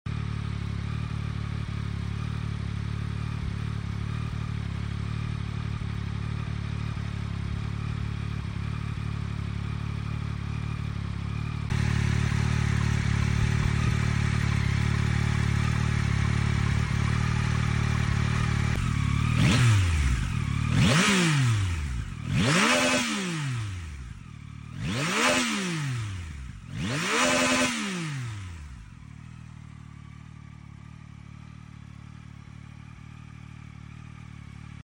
Yamaha MT09 2024 + pure stock exhaust sound + nature